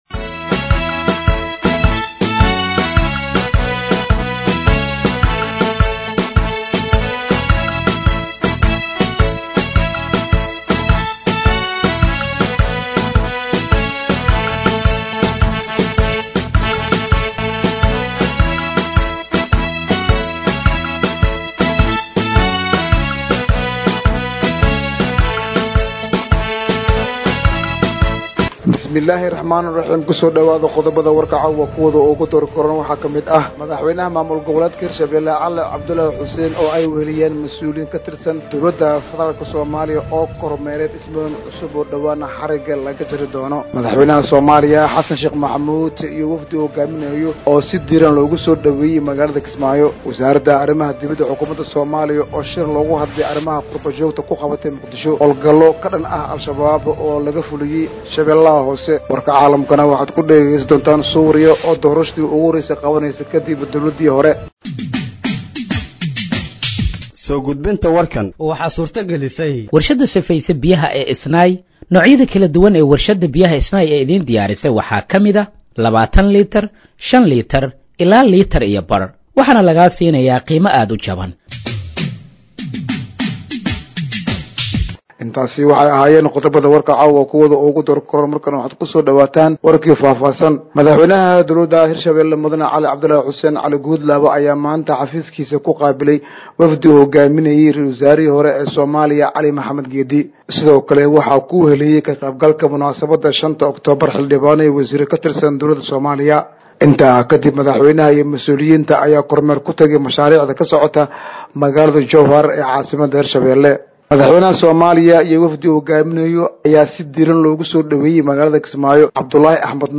Dhageeyso Warka Habeenimo ee Radiojowhar 05/10/2025